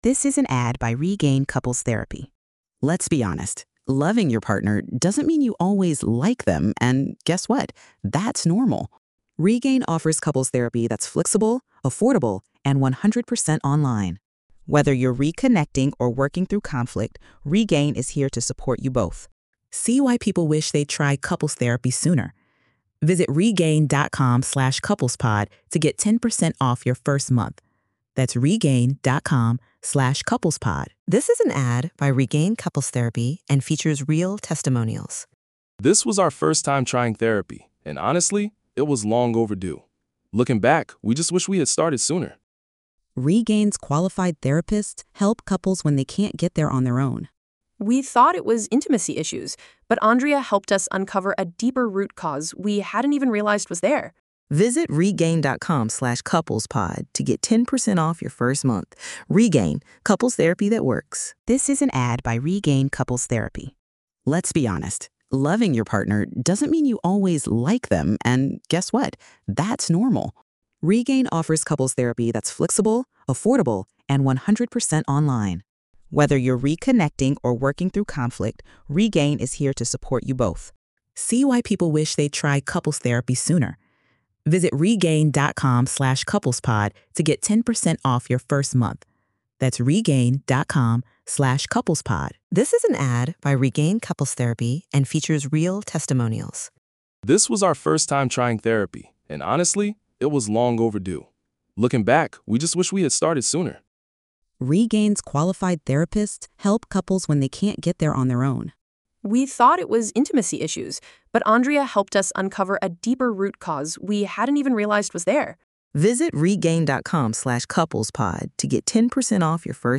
Police Interrogation